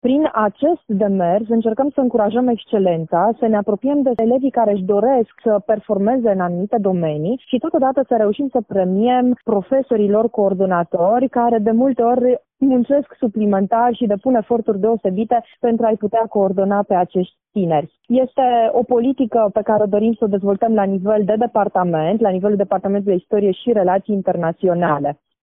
Festivitatea de premiere a avut loc în Aula universității, în prezența profesorilor îndrumători, ai studenților și ai cadrelor universitare.